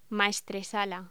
Locución: Maestresala
voz
Sonidos: Hostelería